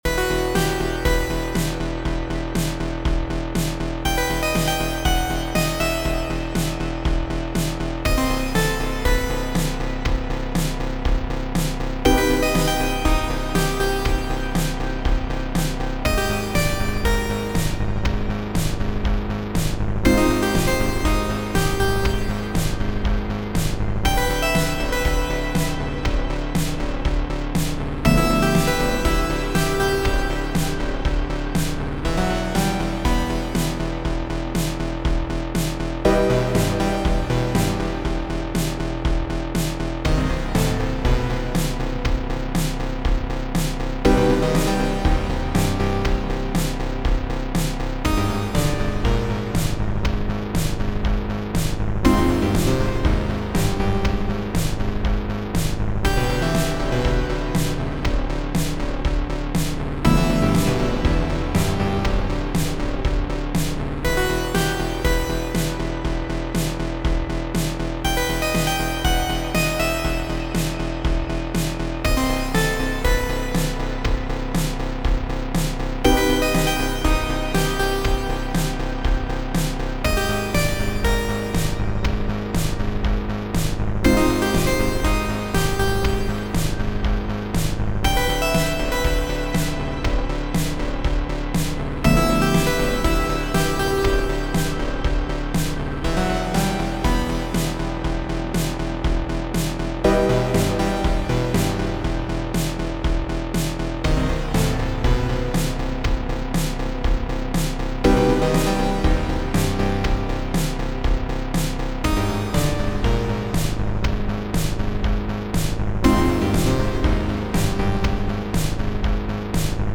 Synth Wave April 3 ,2022